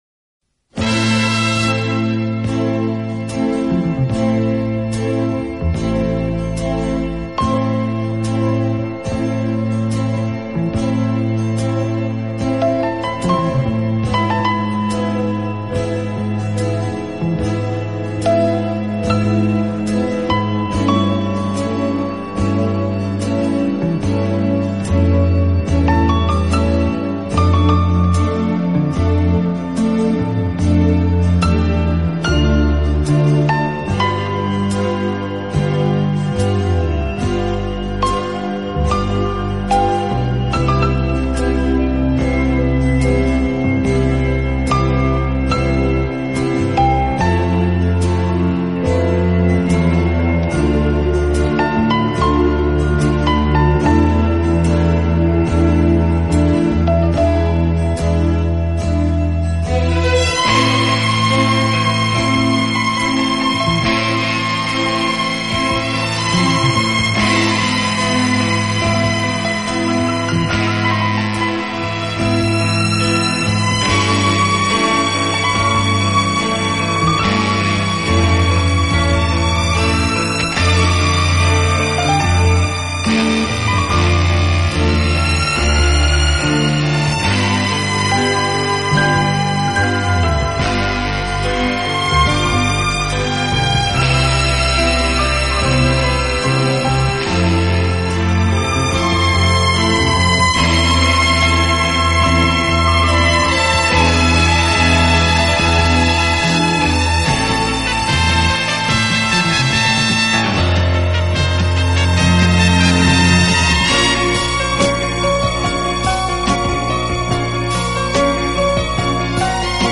【轻音乐】
他以清新、明快的音乐风格，浪漫、华丽的
每当你听到那种独特、新鲜、浪漫的弦乐音响，明晰、活泼的节奏和铜管、打击乐器的